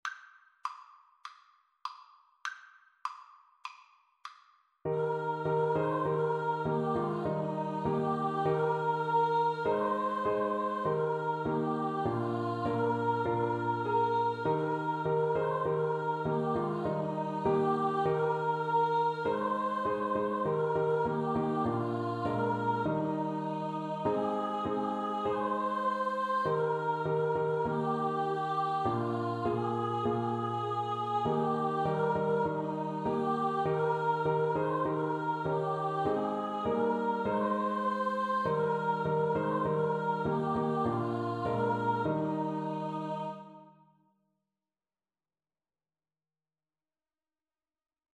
Free Sheet music for Choir (SATB)
4/4 (View more 4/4 Music)
Classical (View more Classical Choir Music)